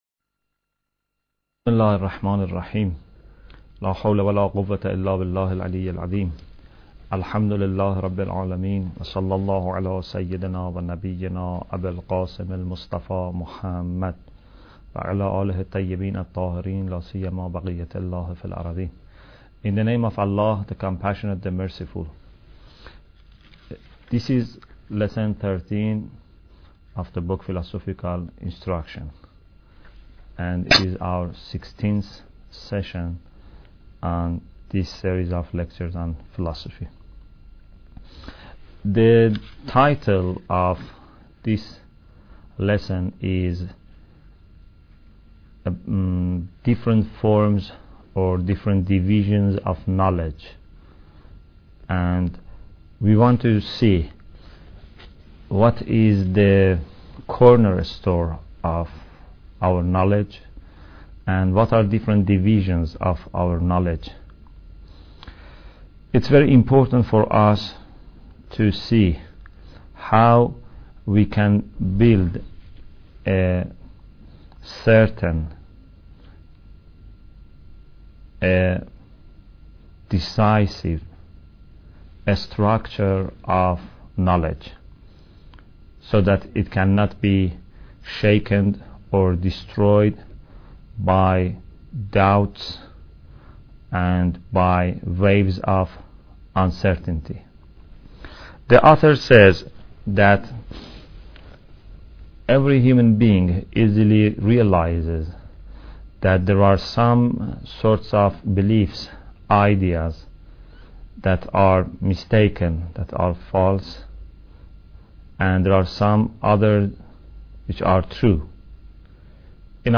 Bidayat Al Hikmah Lecture 16